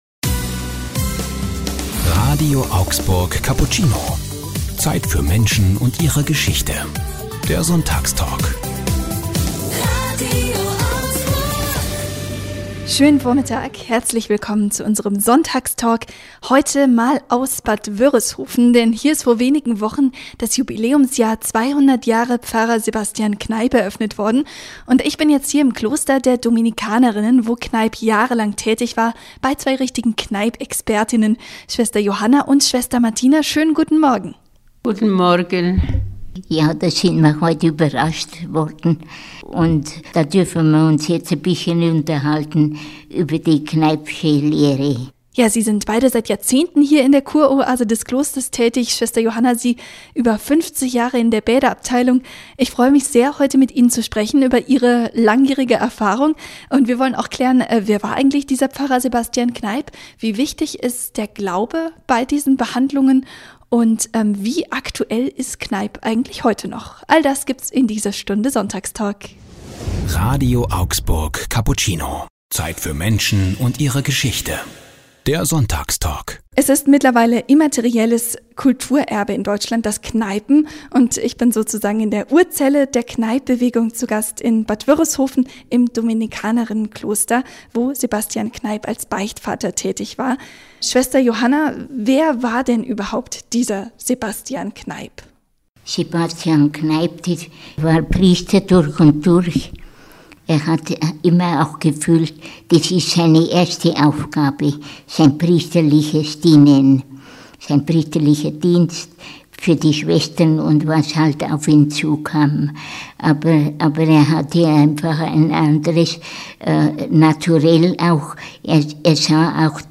Geburstags berichten sie im Sonntagstalk "Cappuccino" über ihre Erfahrungen mit den Anwendungen, welche Rolle der Glaube dabei spielt und warum die Kneipp-Kur heute aktueller ist denn je.